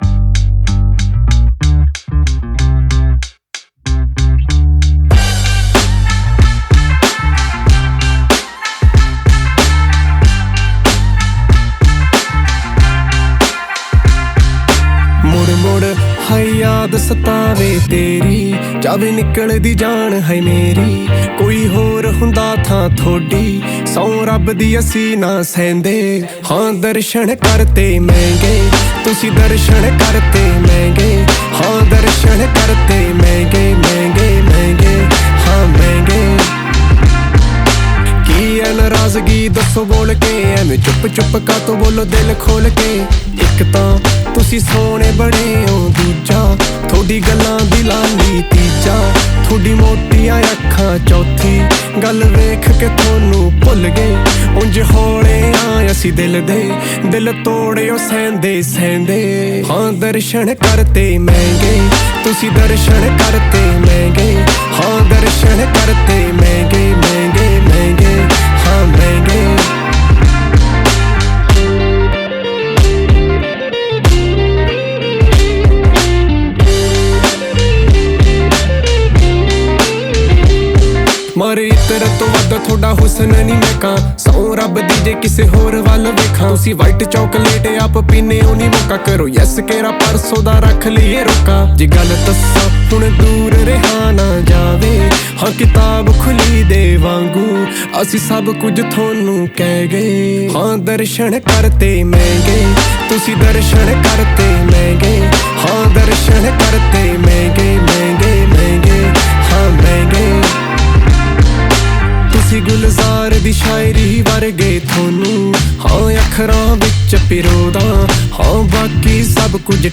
Category: Punjabi